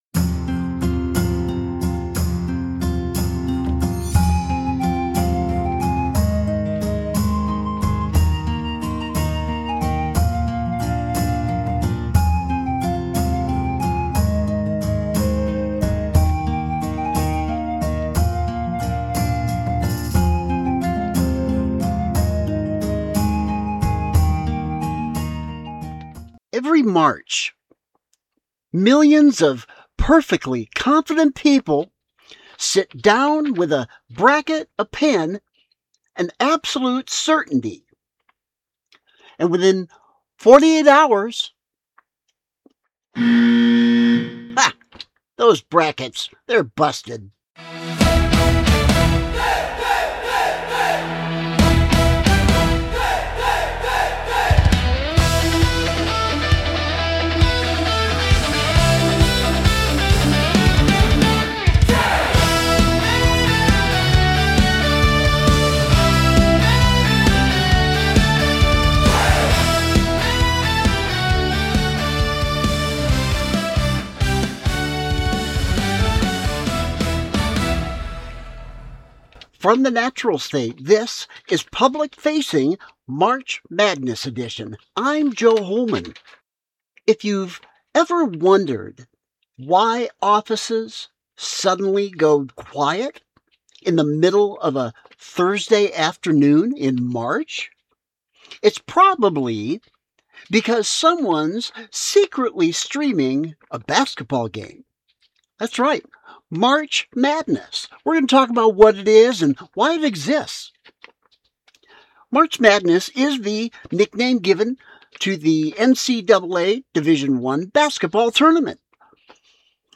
Join me for a simplified observation followed by a simple explainer on the phenomenon known as "March Madness". This one gets a bit animated, but its still tame.&nbsp